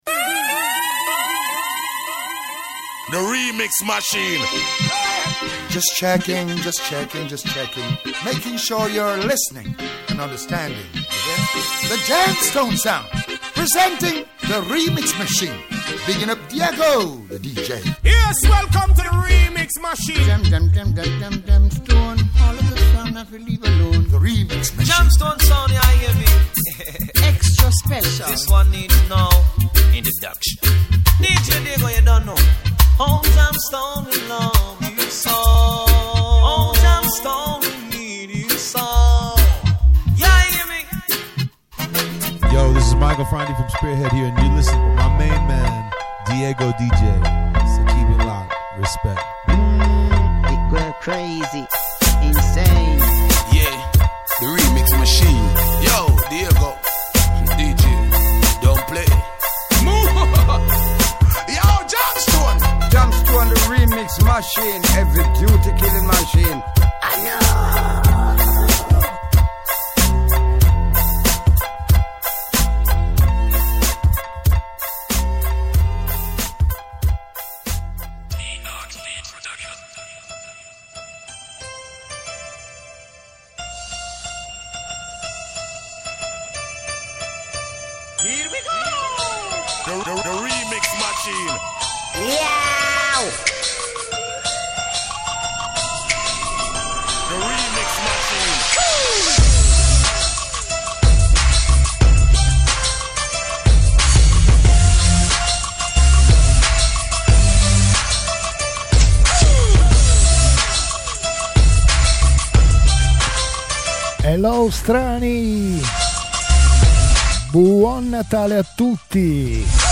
[XMAS SPECIAL]